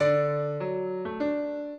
piano
minuet15-11.wav